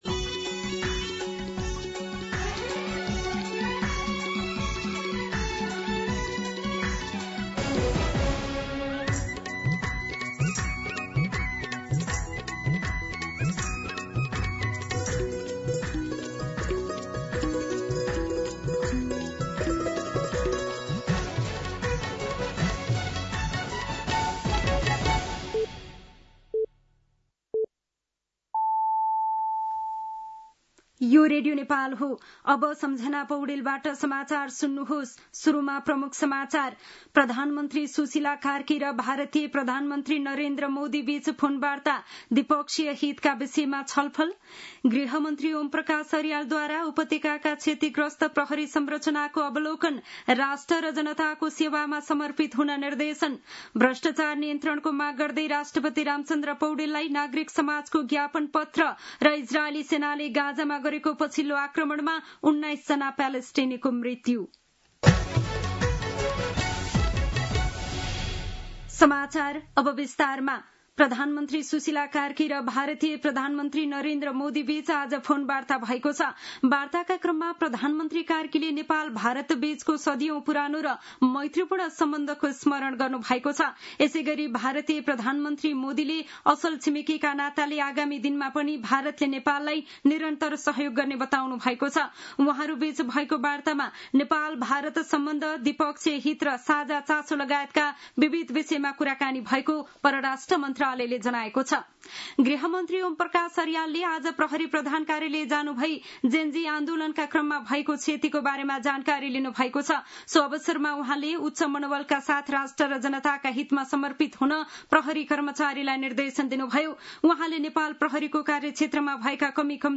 दिउँसो ३ बजेको नेपाली समाचार : २ असोज , २०८२